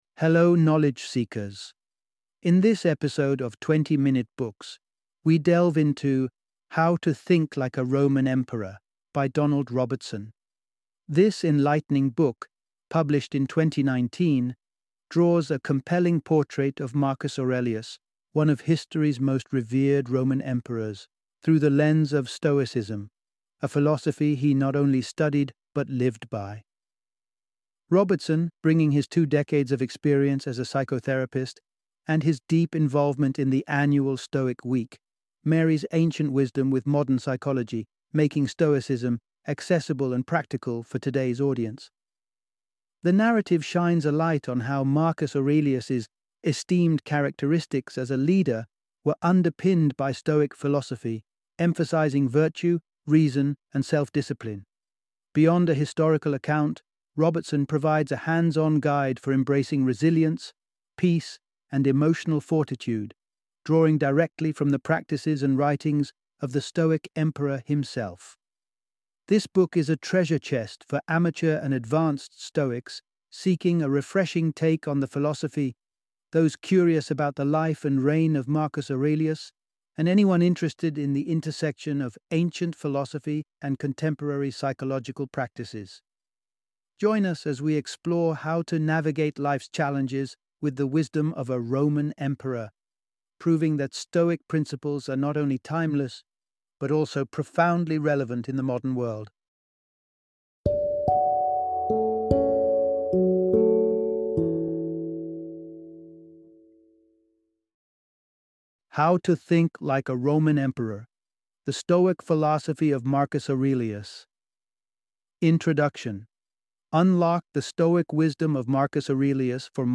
How to Think Like a Roman Emperor - Audiobook Summary